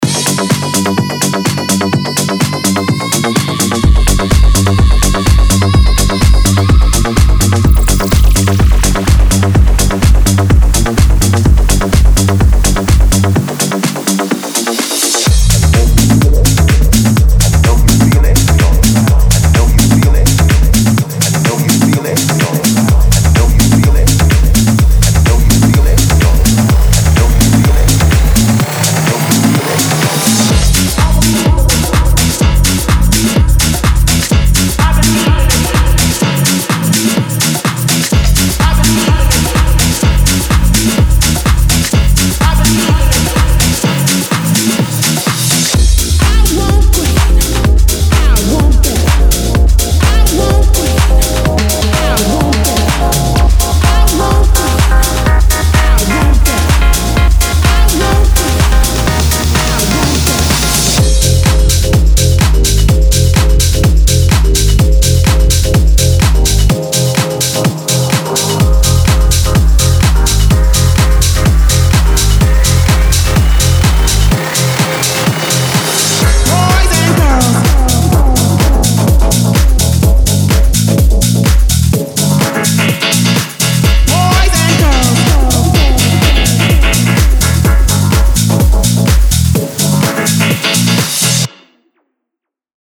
•14 x Male Vocal Phrases
•9 x Female Vocal Phrases
•30 x Full Drum Loops
•30 x Bass Loops
•30 x Synth Loops